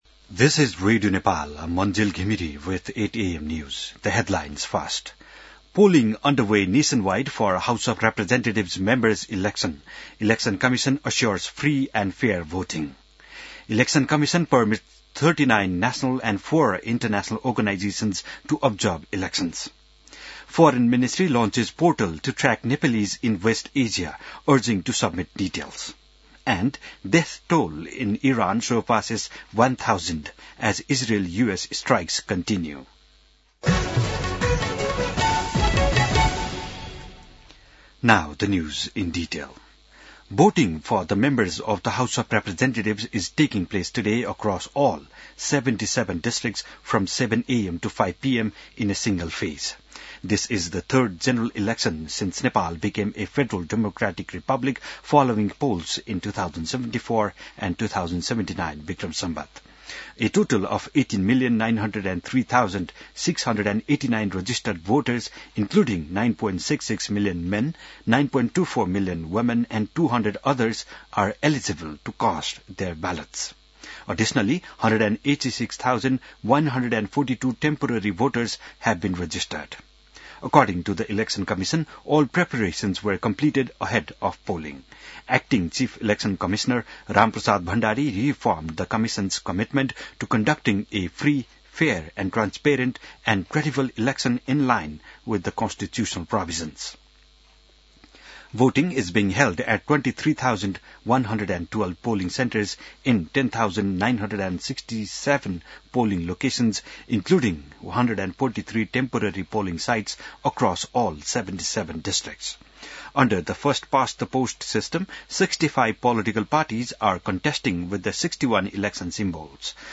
बिहान ८ बजेको अङ्ग्रेजी समाचार : २१ फागुन , २०८२